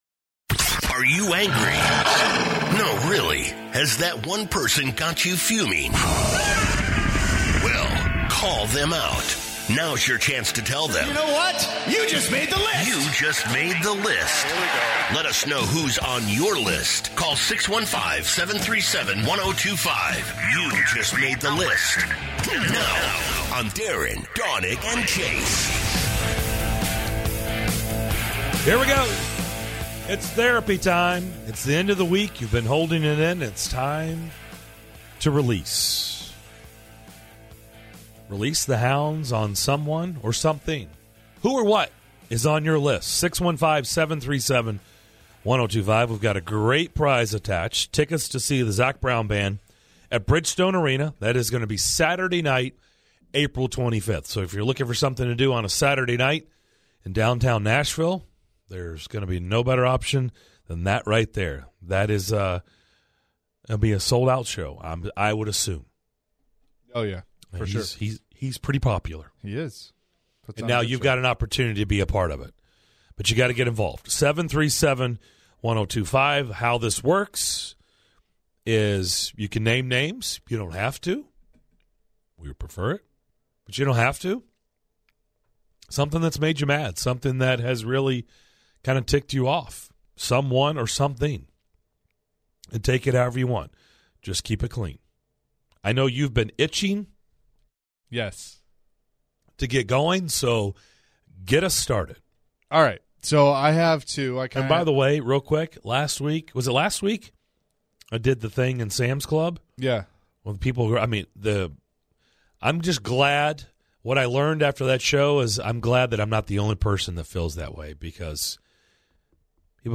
In the third hour of Friday's show; listeners call in to vent on who made them angry in this week's "You Just Made The List".